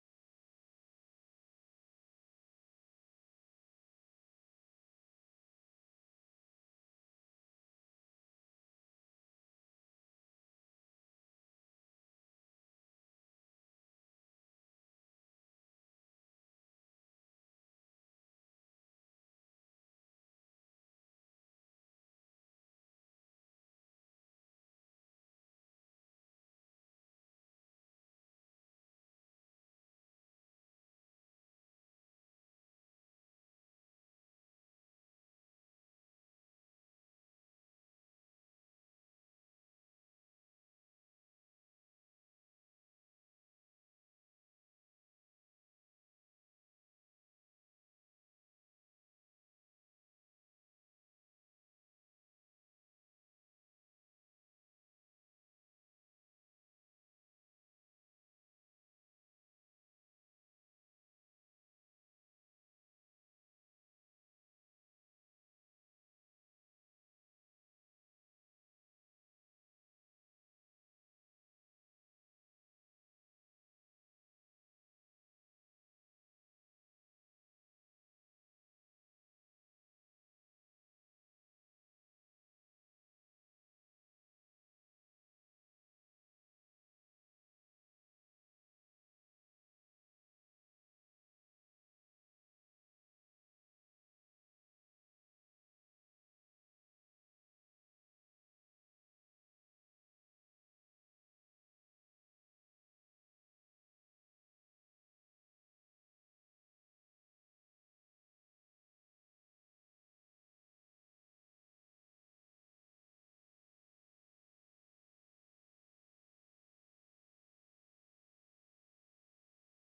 September 01 2024 Praise and Worship
Join us for our weekly service in-person or online starting at 9:45 A.M. every Sunday.